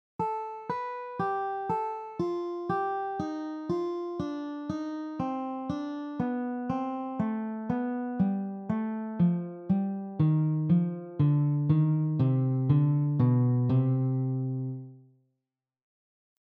Descending 3rds scale pattern
This is the same concept as before except we are descending in thirds using the C melodic minor scale.